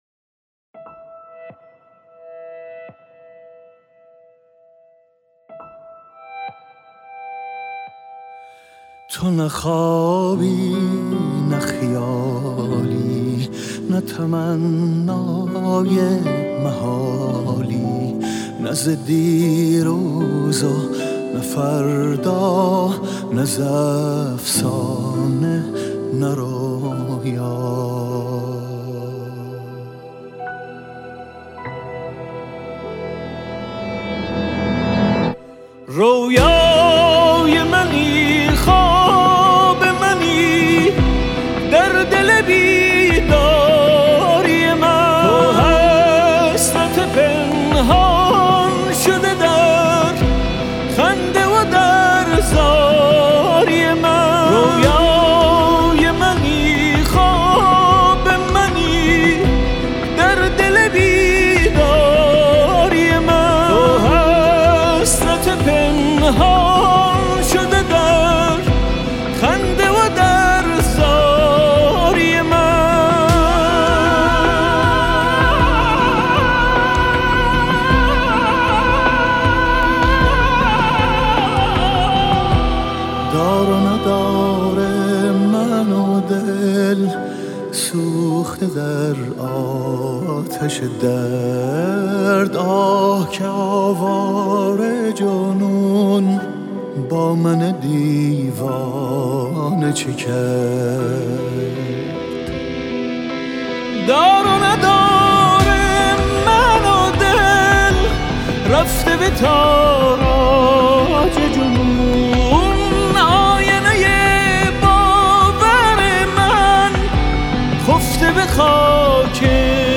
ژانر: سنتی